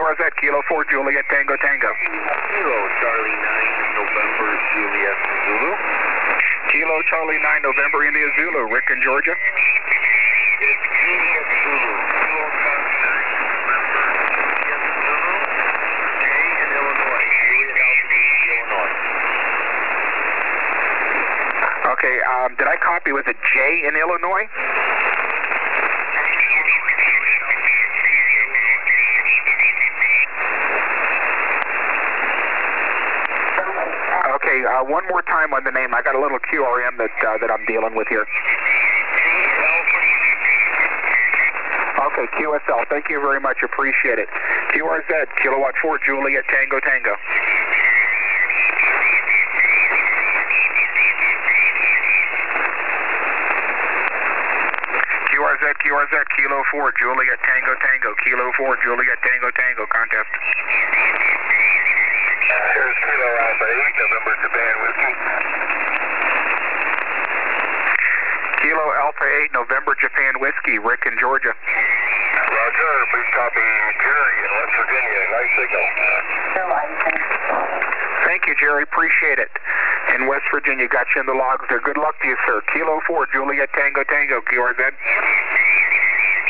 station was "booming in"  After listening to this file all I can say is "not